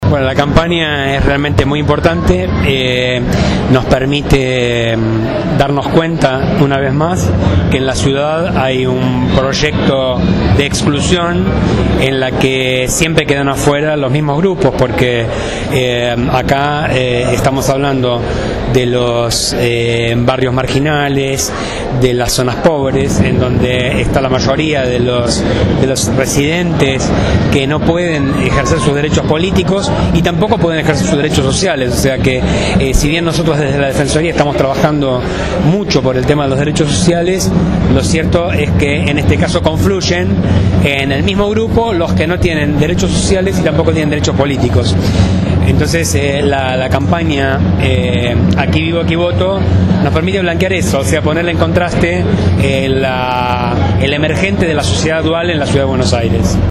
El Defensor General Adjunto en el fuero Penal, Contravencional y de Faltas Roberto Andres Gallardo acompañó esta presentación y habló con Radio Gráfica de la importancia de la campaña «Aquí vivo aquí voto».